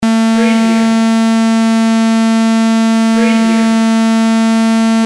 Beep sound effect .wav #20
Description: A simple beep sound effect
Keywords: beep, beeps, beeping, single, simple, basic, interface, flash, game, multimedia, animation, menu, button, navigation, electronic
beep-preview-20.mp3